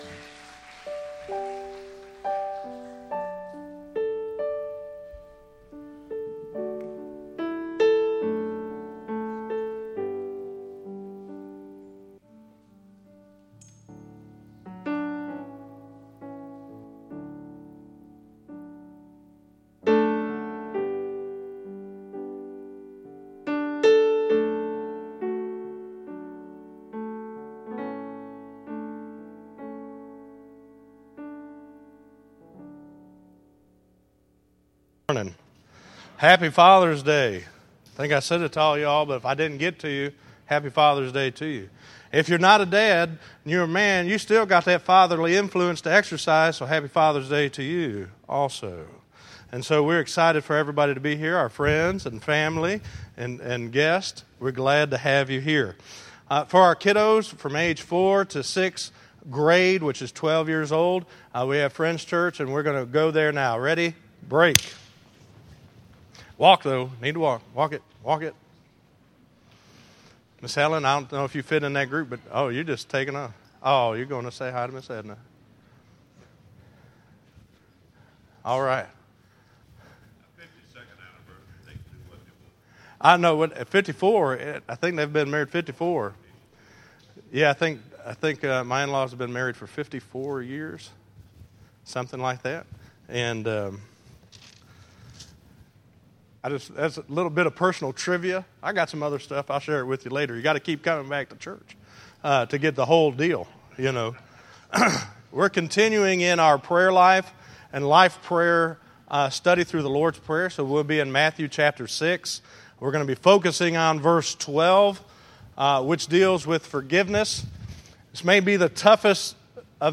Listen to Prayer Life = Life Prayer 4 - 06_15_2014_sermon.mp3